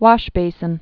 (wŏshbāsən, wôsh-)